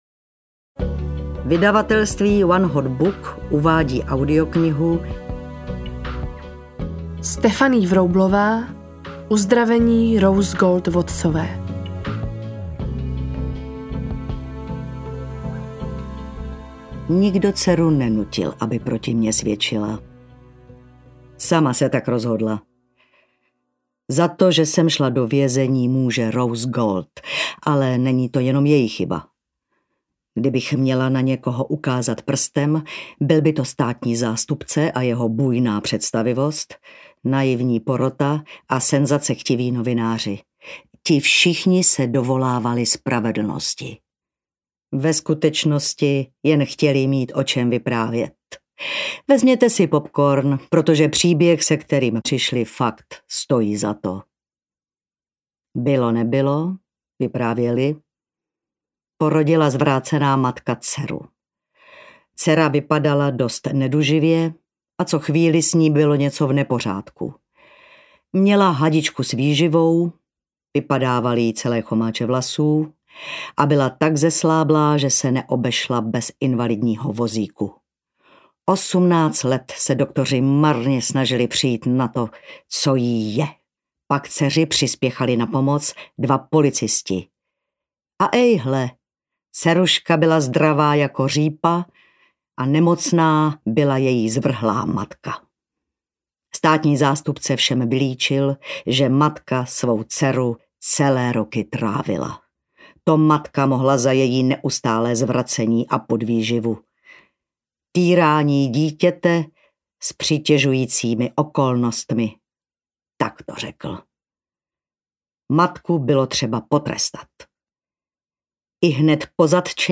Uzdravení Rose Gold Wattsové audiokniha
Ukázka z knihy